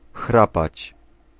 Ääntäminen
Synonyymit träumen Ääntäminen Tuntematon aksentti: IPA: /ˈʃnaʁçən/ IPA: [ˈʃnaɐ̯çn̩] IPA: /ˈʃnaʁçən, ˈʃnaʁçən/ Haettu sana löytyi näillä lähdekielillä: saksa Käännös Ääninäyte 1. chrapać Esimerkit Ich schnarche gern.